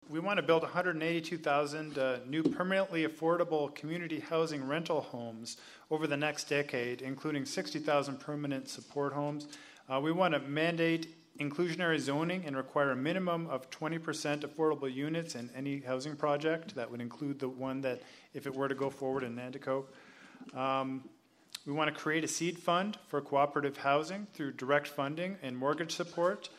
The Haldimand-Norfolk candidates met at the Royal Canadian Legion in Simcoe on Thursday night.